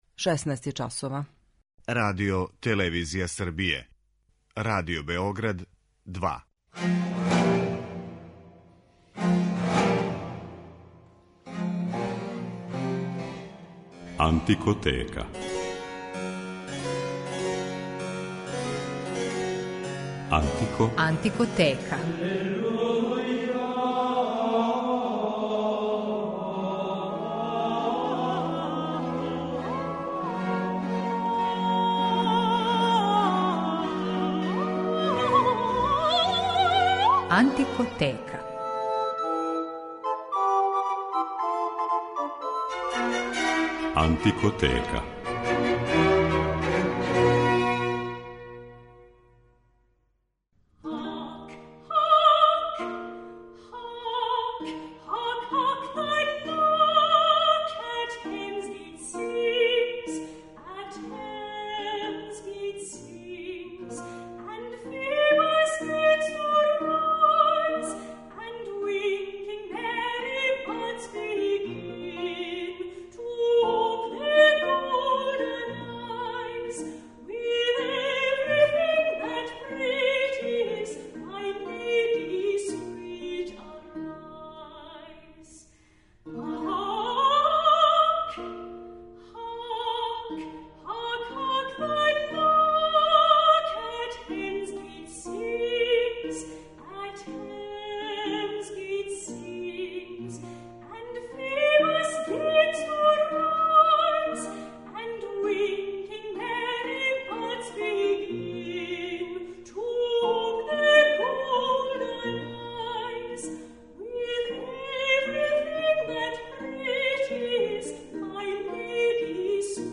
Шекспир и музика је тема данашње емисије у којој ћете моћи да слушате многе од песама и игара које је овај велики писац вероватно употребљавао у својим драмама.